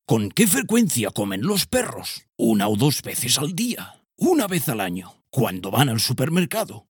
TEST HISTORIA PERRO-Narrador-10_0.mp3